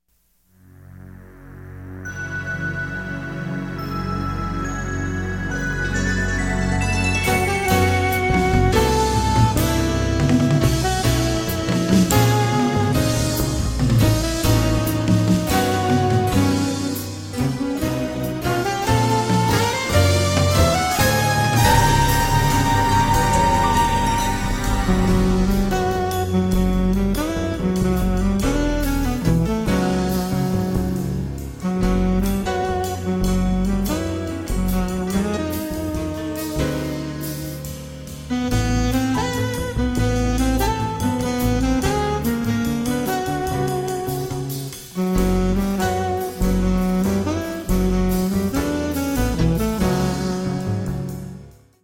piano and synths
sax
acoustic bass
drums